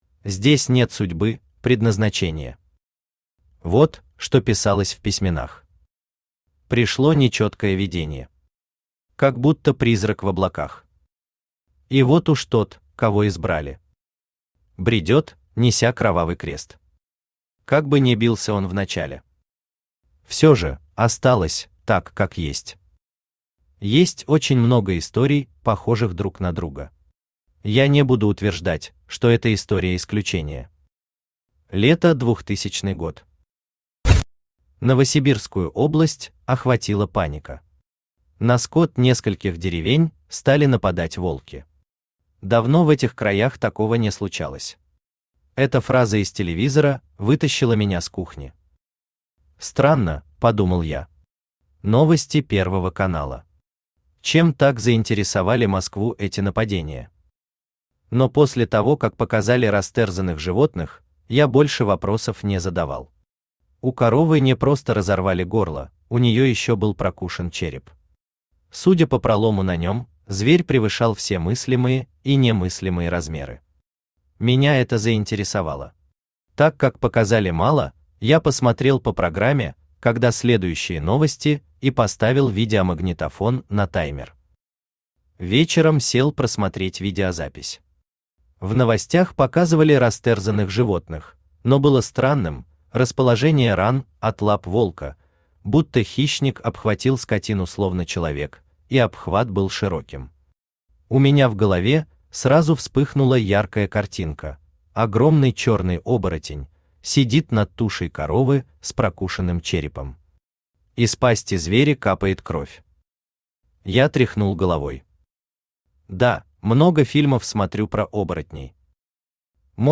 Аудиокнига Исповедь зверя | Библиотека аудиокниг
Aудиокнига Исповедь зверя Автор Сергей Витальевич Шакурин Читает аудиокнигу Авточтец ЛитРес.